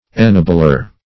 Ennobler \En*no"bler\, n. One who ennobles.